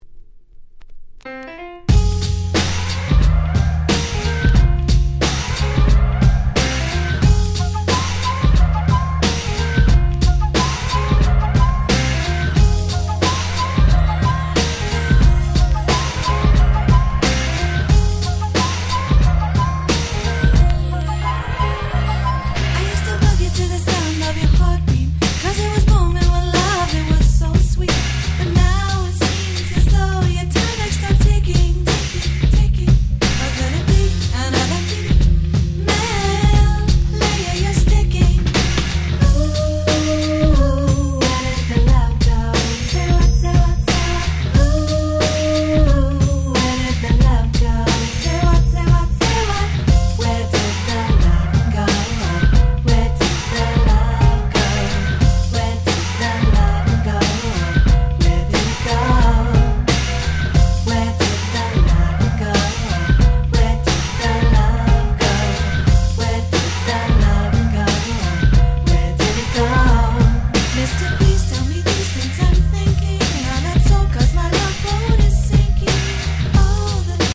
94' Very Nice R&B / Hip Hop Soul !!